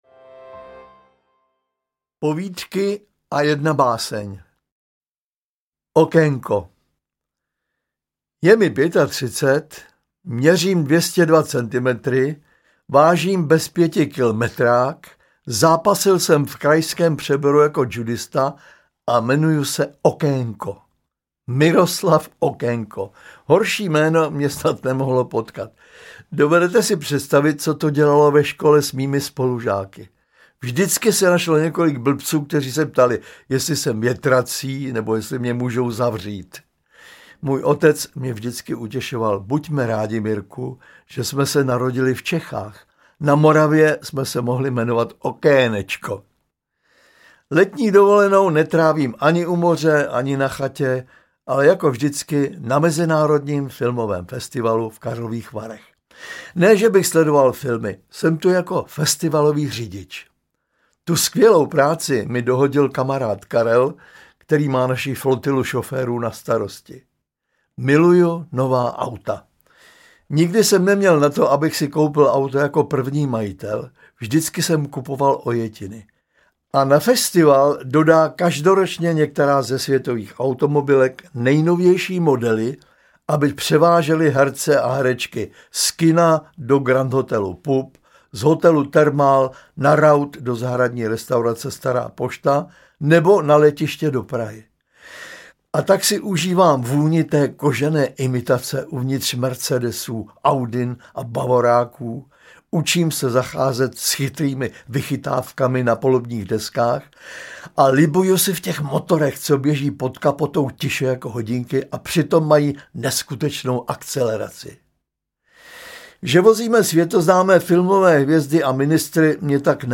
Povídky a jedna báseň audiokniha
Ukázka z knihy
• InterpretZdeněk Svěrák